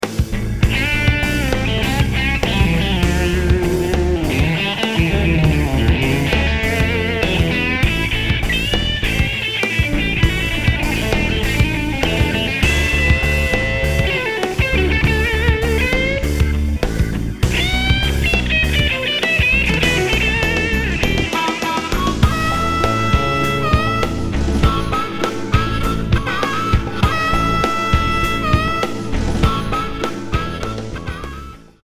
100 BPM